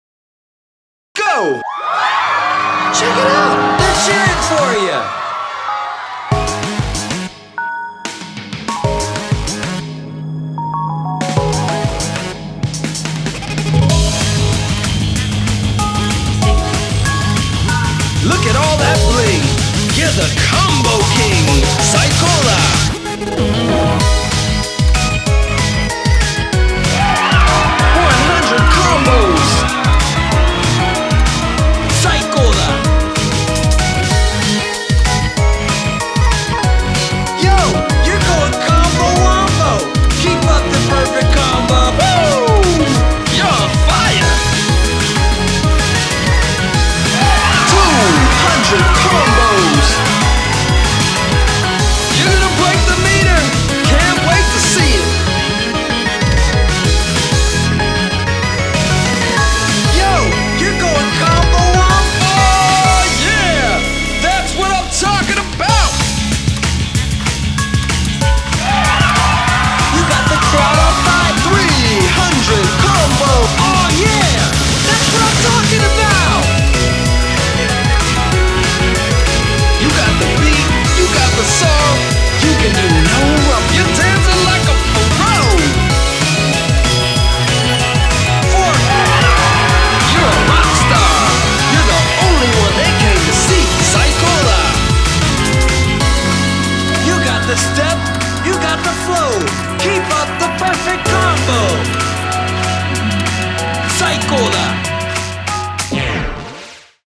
BPM190
Audio QualityLine Out
Audio - line out